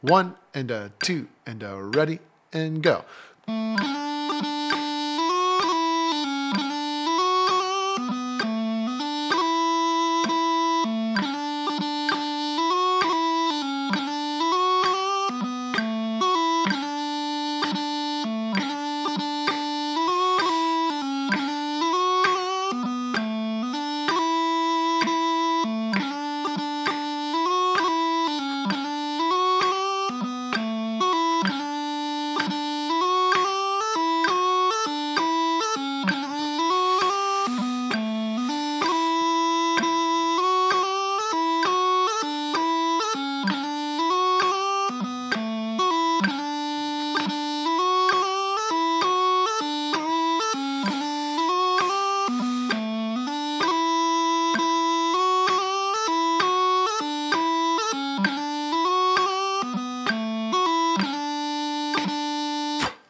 EUSPBA 60th Jubilee - 65bpm.mp3